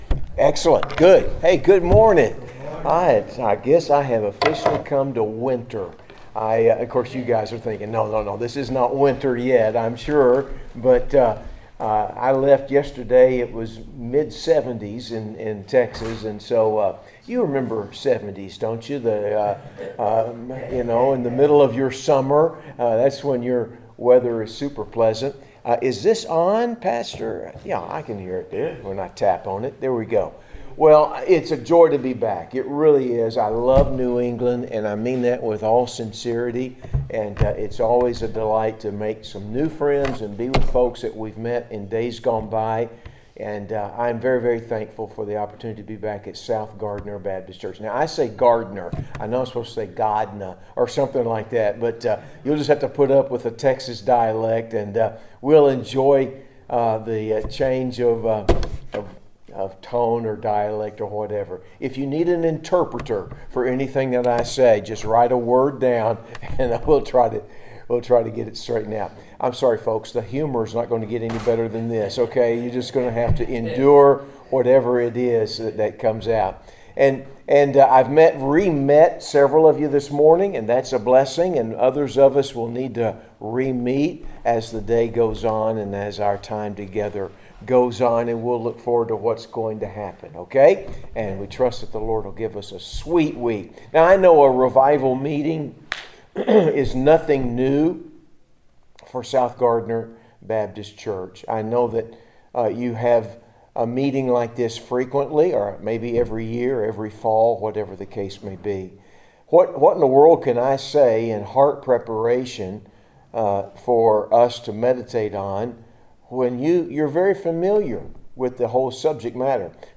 November 2025 Isaiah Sunday School Scripture: Isaiah 6:1-5 Download: Audio